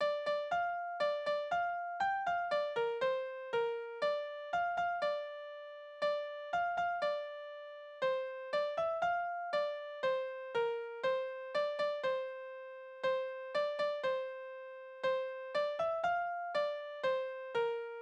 Kinderspiele: Krieche durch!
Tonart: F-Dur, D-Dur, B-Dur
Taktart: 2/4
Tonumfang: große Sexte